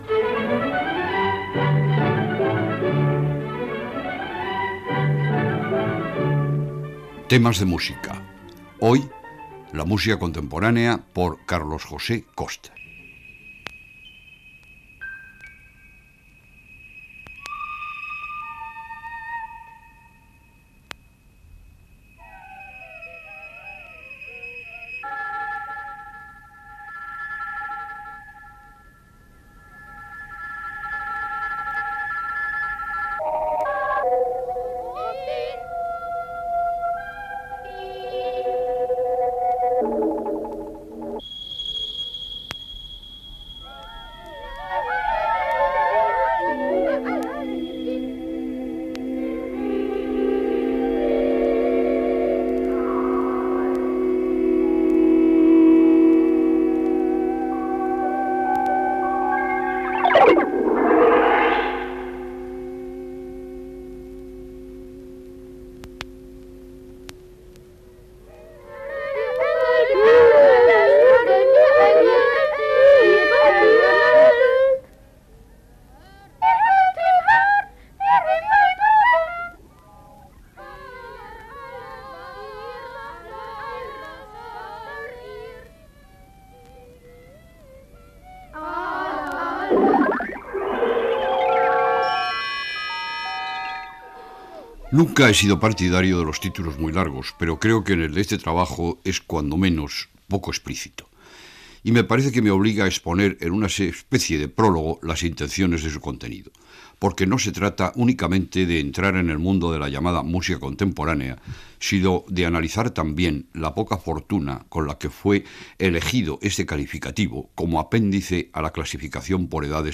Careta del programa i espai dedicat a la música contemporània
Musical
FM